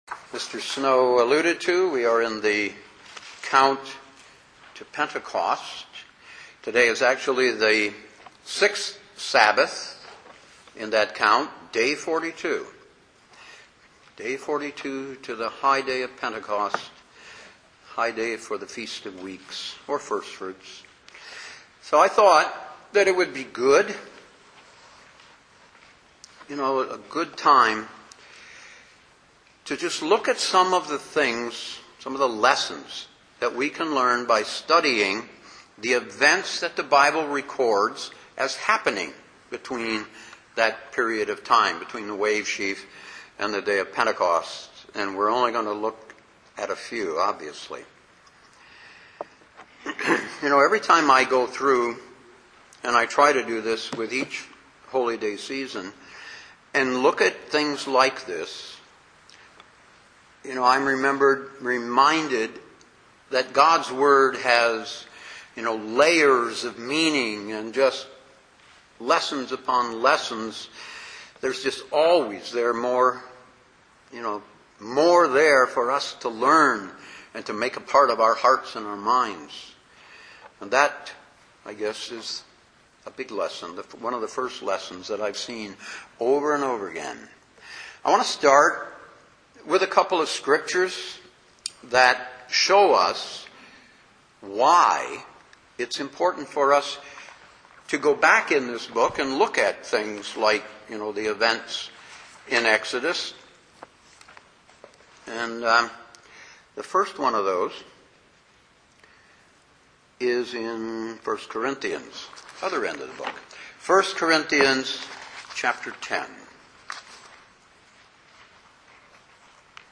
Given in Detroit, MI
UCG Sermon Studying the bible?